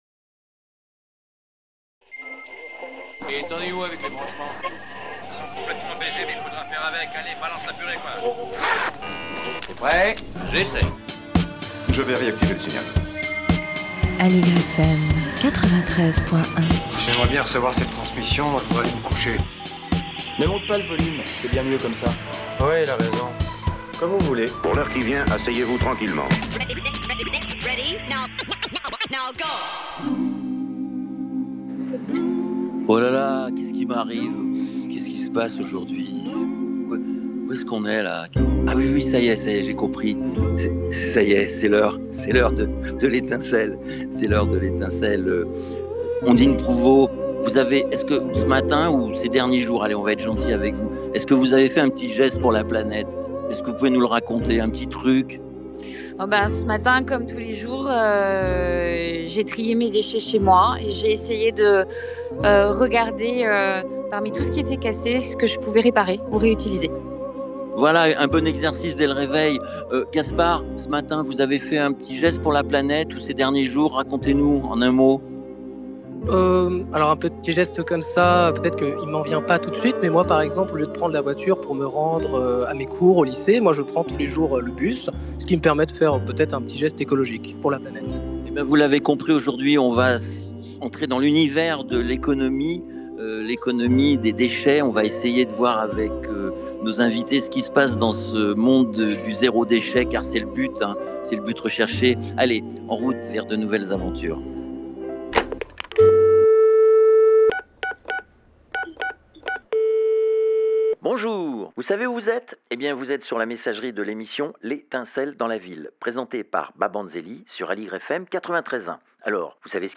Au salon Zéro+, Porte de Versailles à Paris